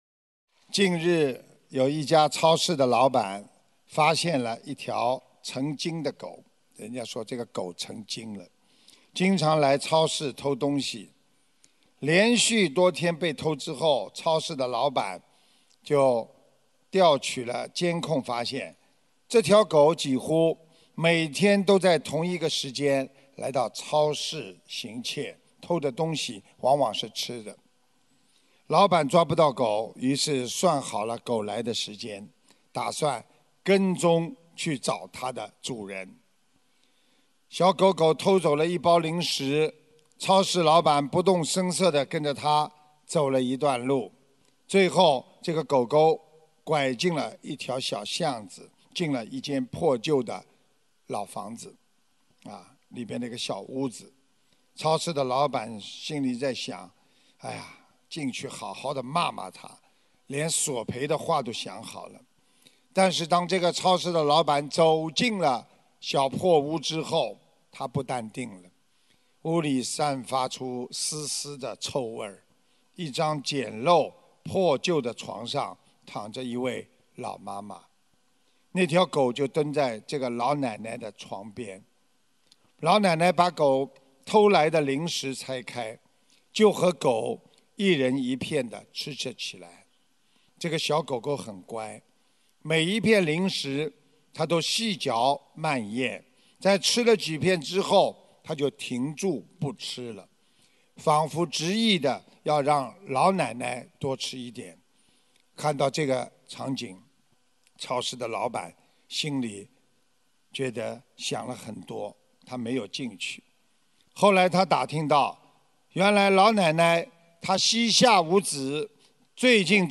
音频：师父讲戒杀故事 _ 成精的狗！【摘录_2018年11月11日墨尔本法会！】